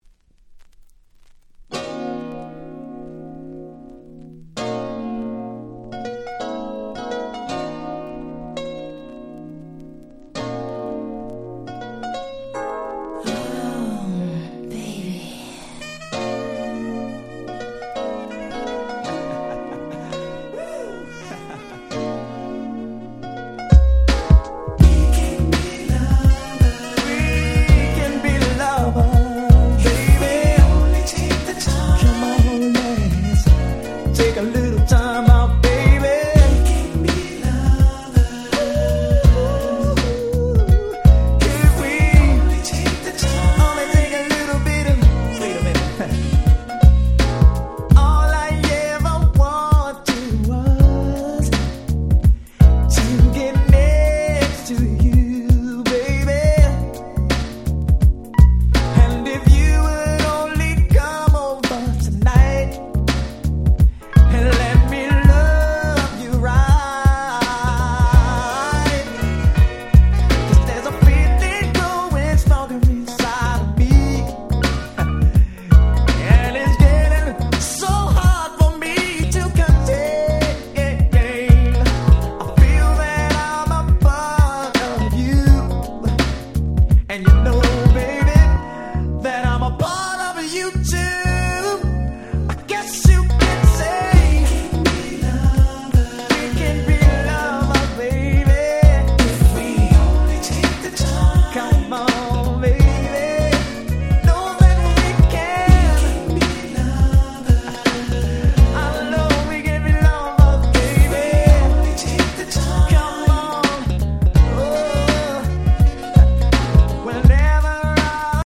93' Very Nice R&B !!
超マイナーですが内容はピカイチなMidダンサー。